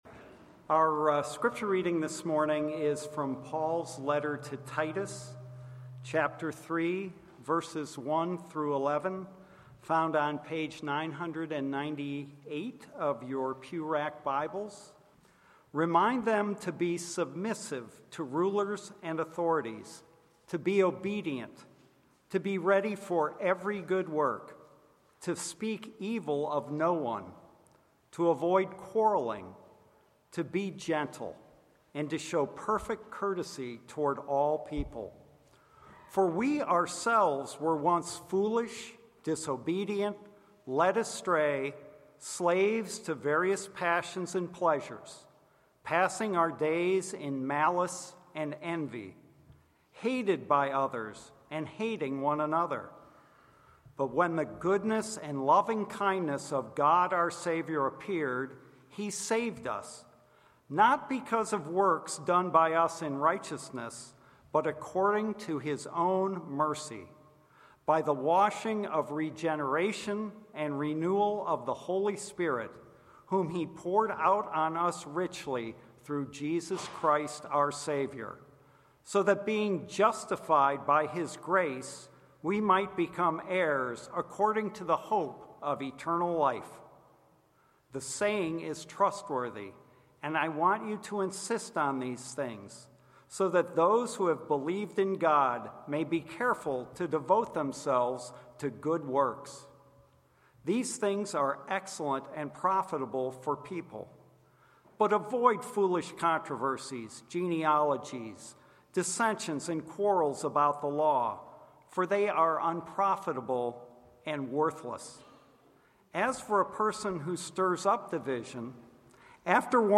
Passage: Titus 3:1-11 Sermon